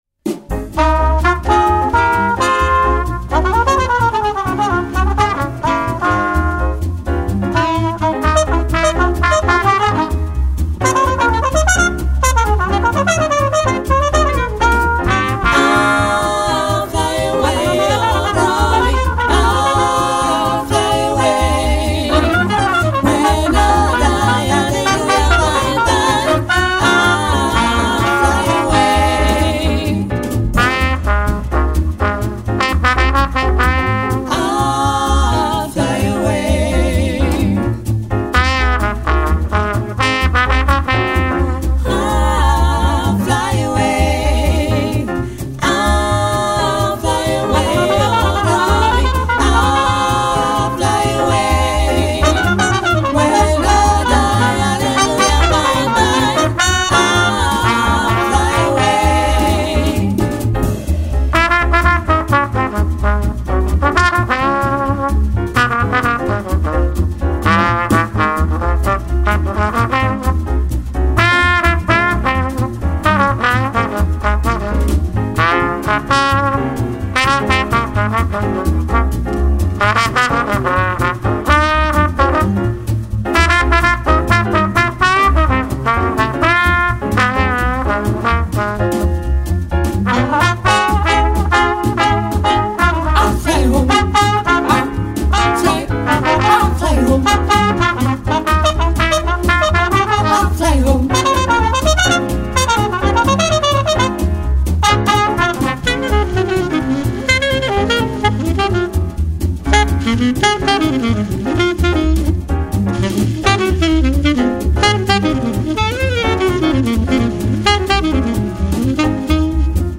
sax alto
trompette
trombone
piano
contrebasse
batterie
chant